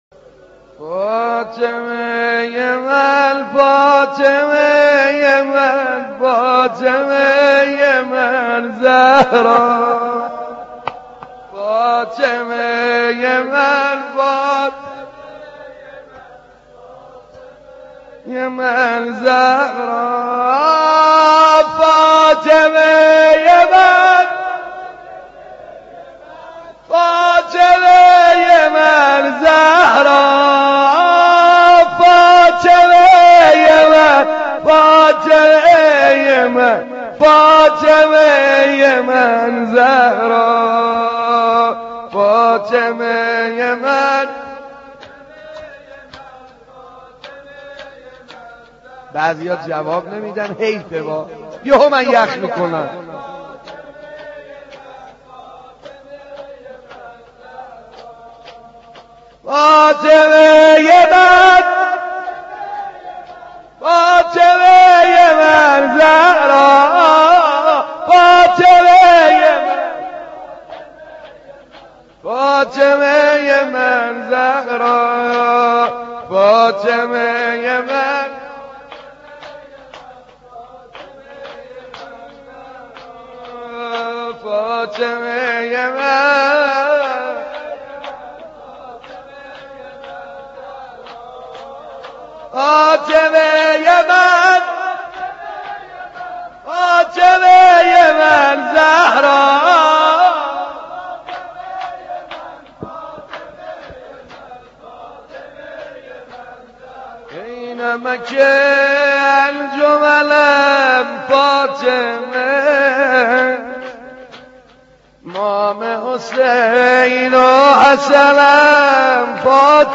نوحه به مناسبت شهادت حضرت فاطمه کبری(س